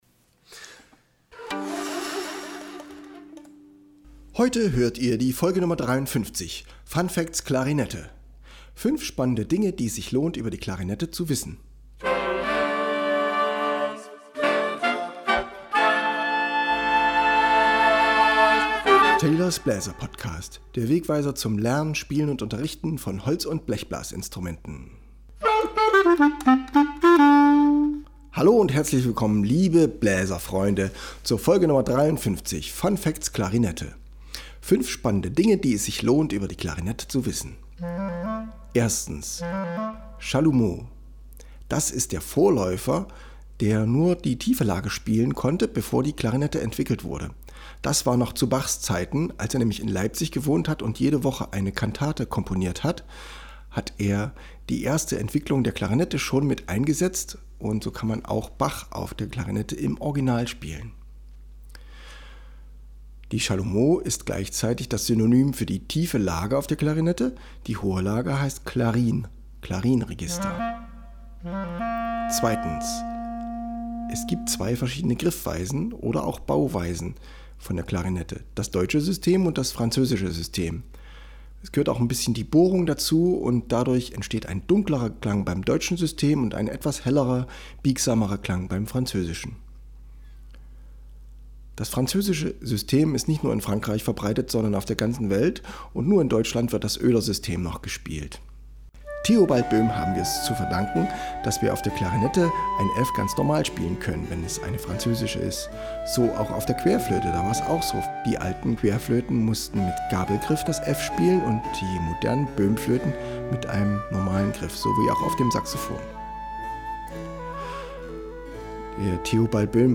Ich spiele Klavier und Klarinette, eine Boehmklarinette mit einem eigentlich zu hellem, leichten Blatt für diese Musik.